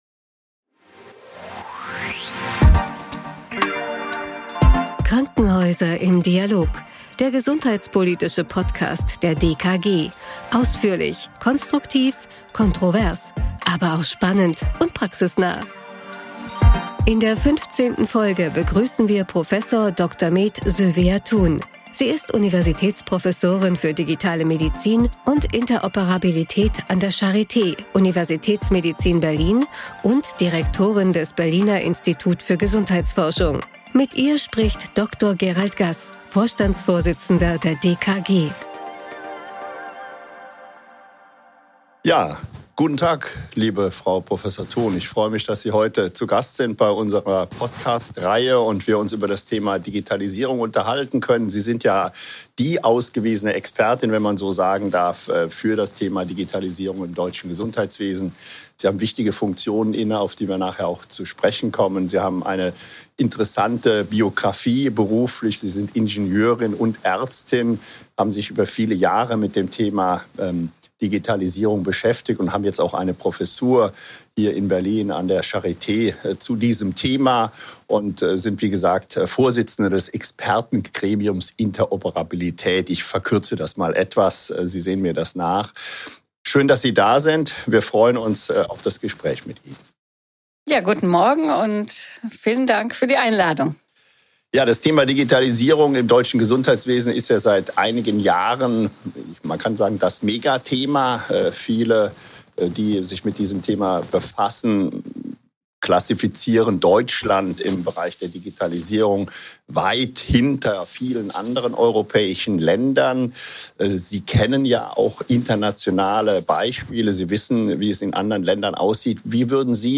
Im Gespräch geht es um Fort- und Rückschritte der Digita...